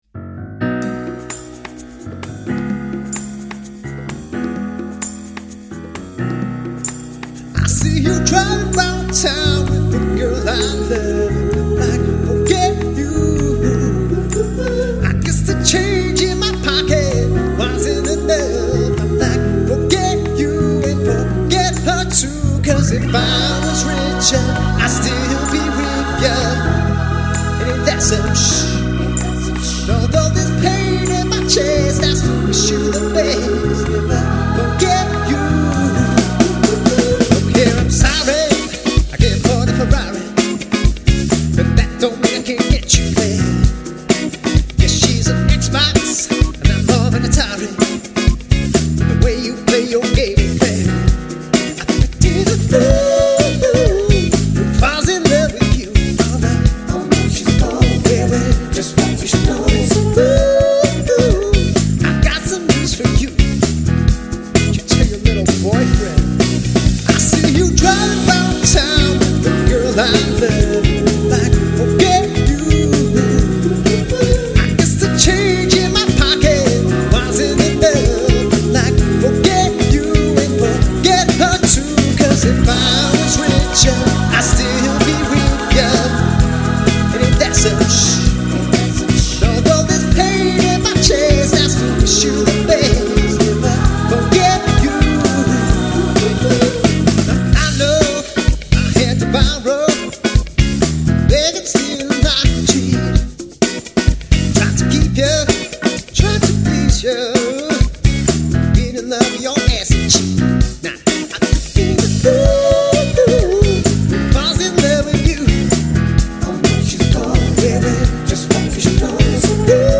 Male and/or Female Lead Vocals